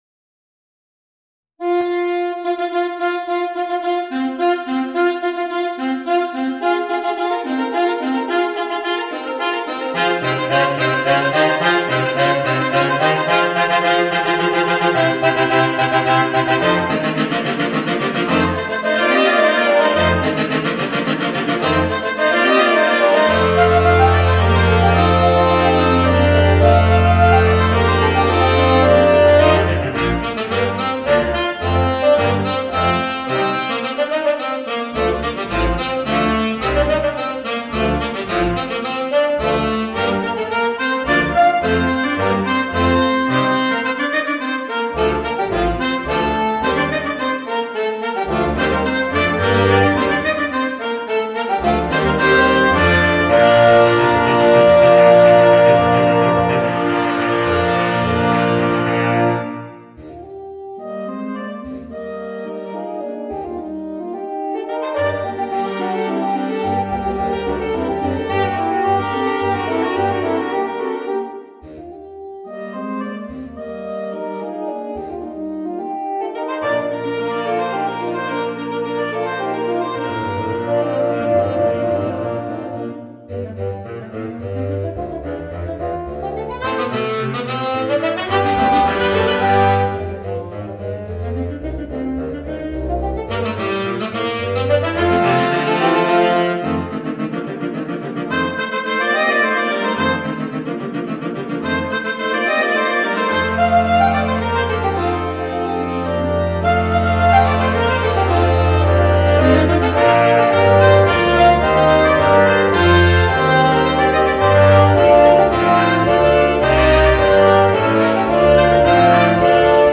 Voicing: 4 Saxophones